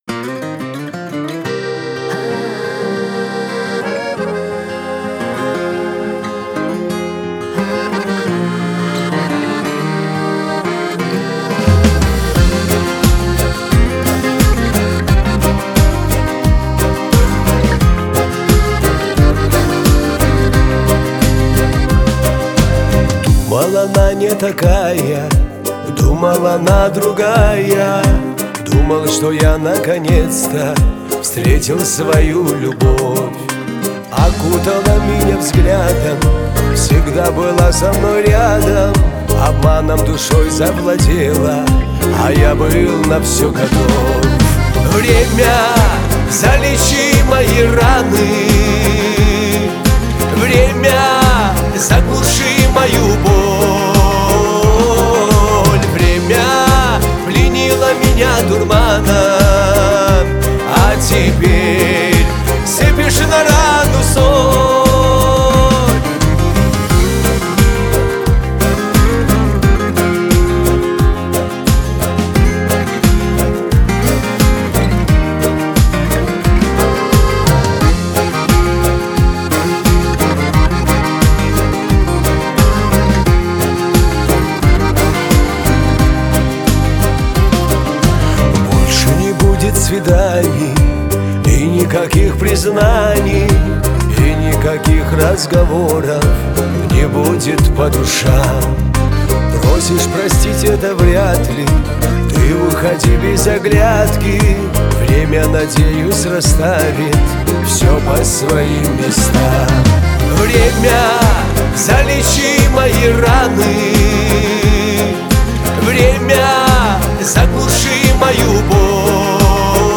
это яркий образец поп-рока